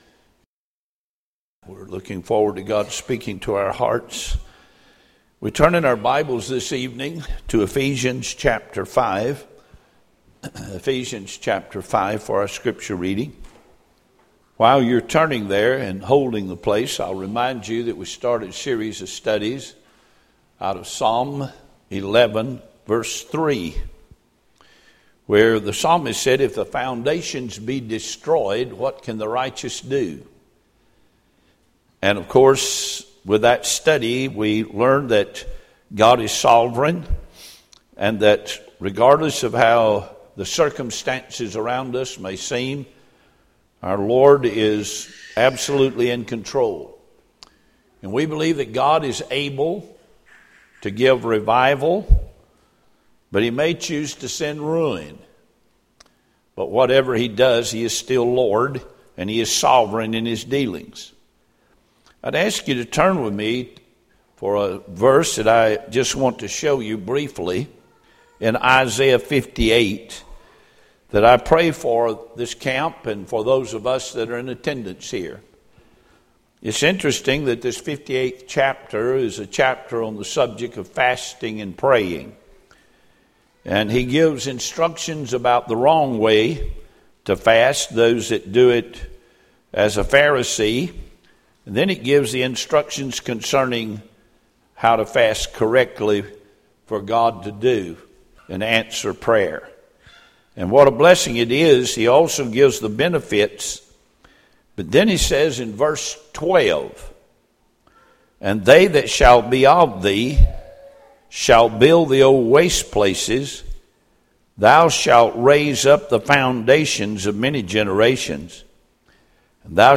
Series: 2015 July Conference Session: Evening Session